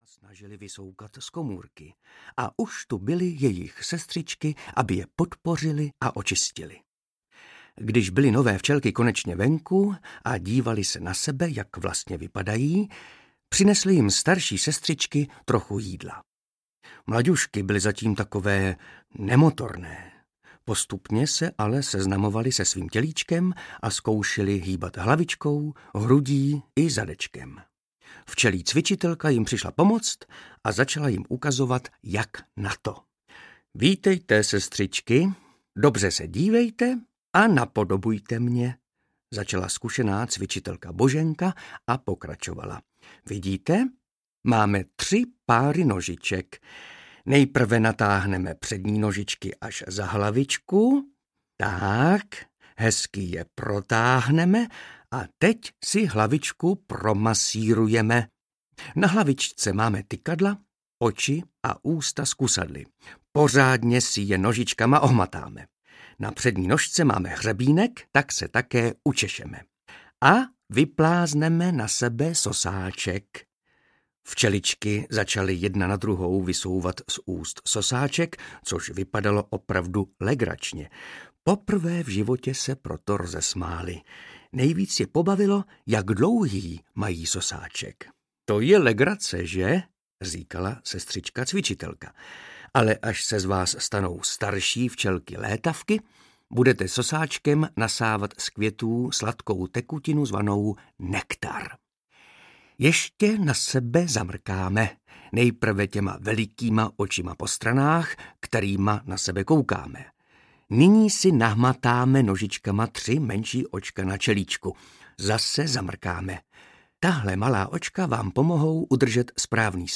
Byl jednou jeden úl audiokniha
Ukázka z knihy
• InterpretMartin Myšička